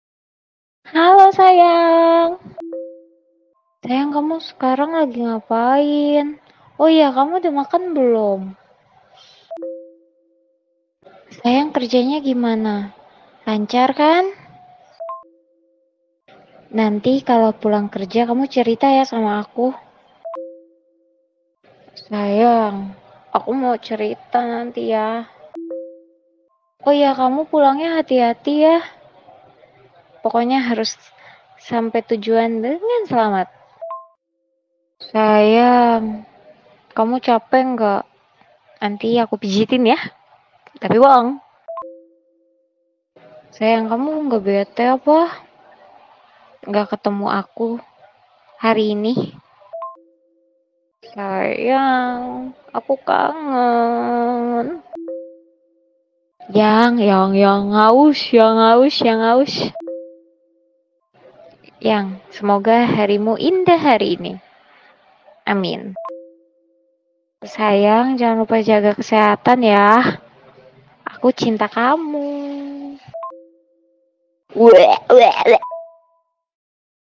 Kategori: Suara manusia
vn-suara-wanita-bilang-sayang-id-www_tiengdong_com.mp3